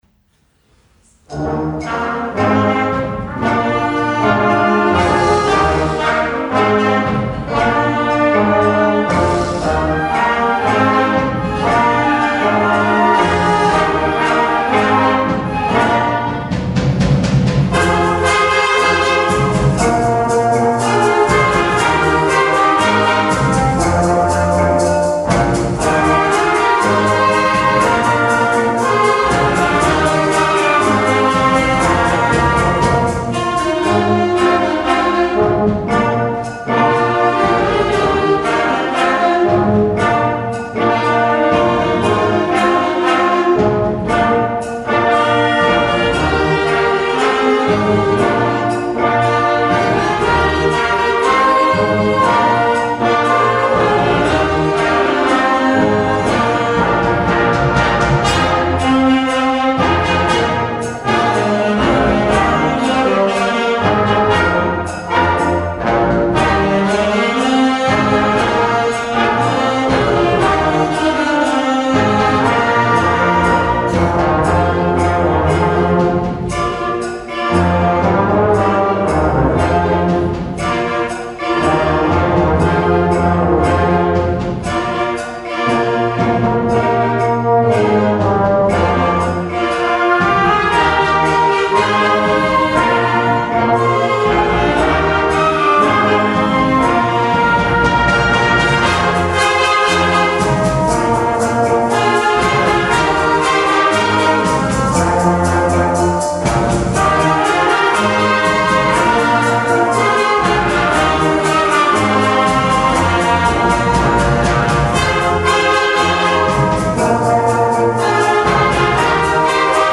Jahreskonzert 2019